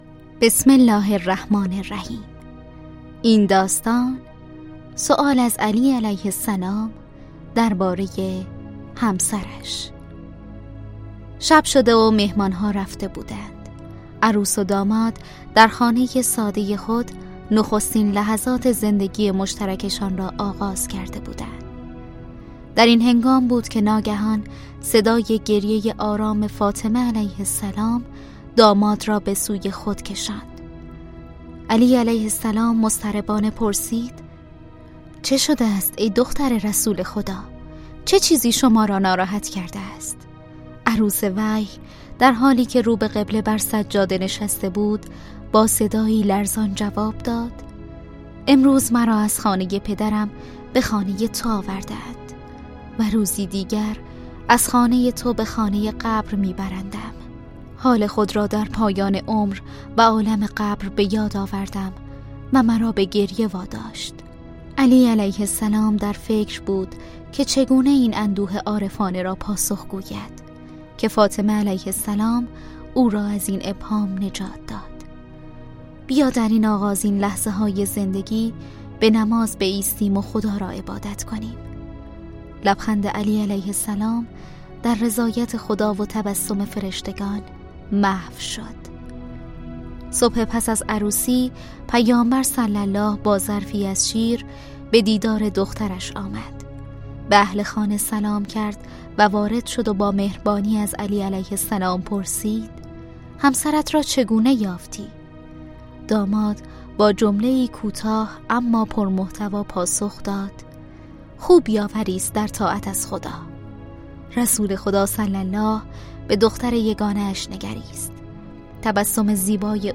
کتاب صوتی مهربانو